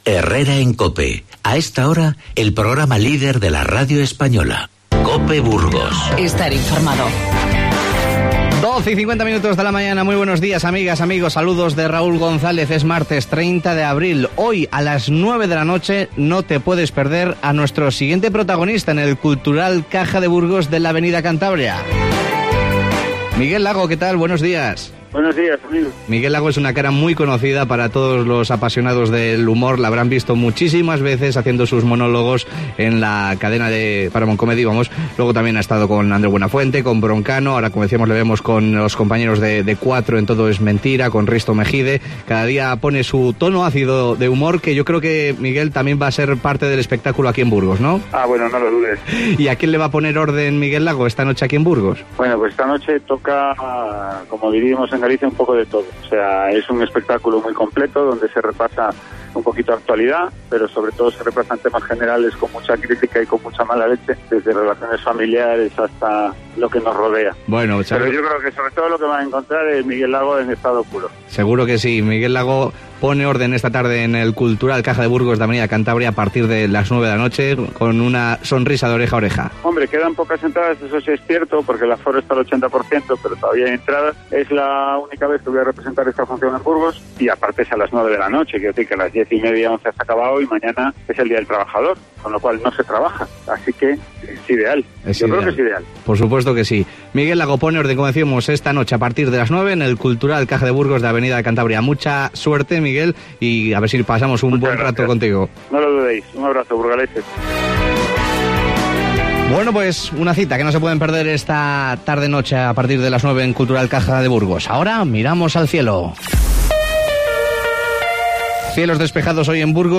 Charlamos con el cómico Miguel Lago, que visita esta tarde Burgos para ofrecernos su último espectáculo y, con Asaja Burgos, nos enteramos del ataque que tuvo lugar ayer de un buitre a una vaca y su novillo a quienes ocasionó su muerte.